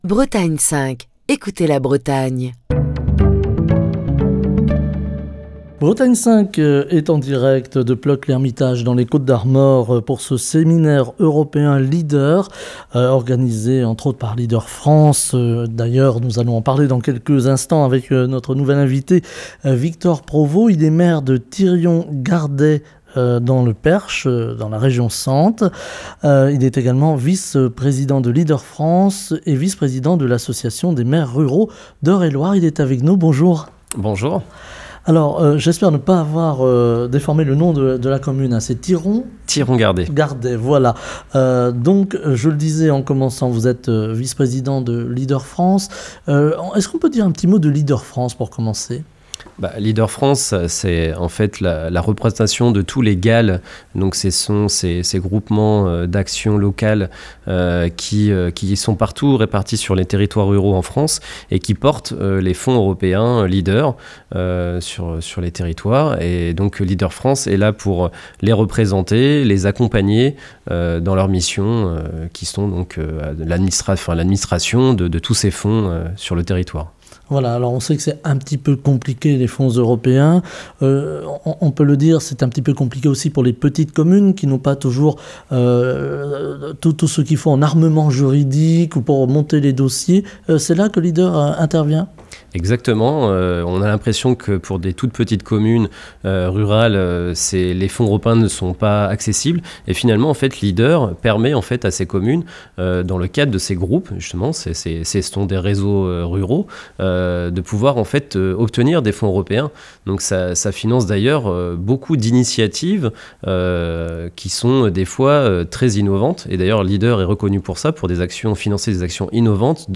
Semaine Europe et ruralité - Bretagne 5 est en direct de Plœuc-L'Hermitage pour le séminaire LEADER.